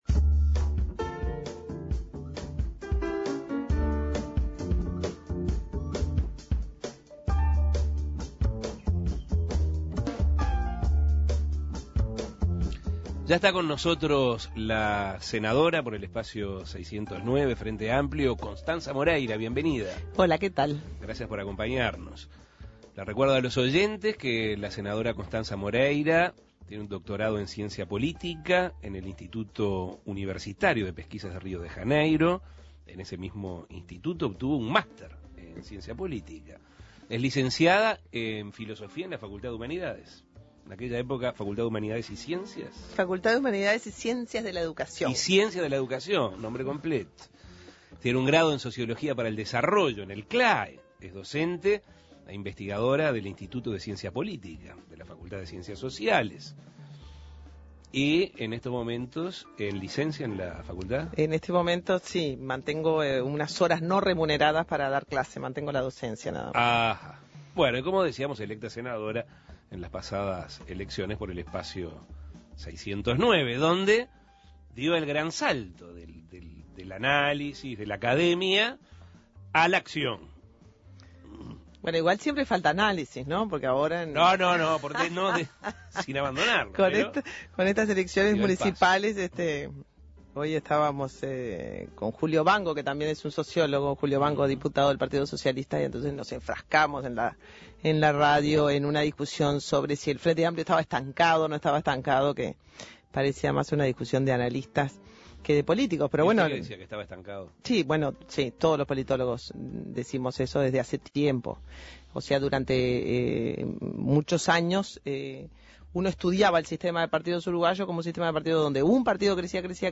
Constanza Moreira, politóloga y diputada del Espacio 609, realizó en la entrevista central de Asuntos Pendientes una autocrítica de la situación actual de la fuerza de gobierno y los desafíos que tiene a futuro, entre otras cosas.
Entrevistas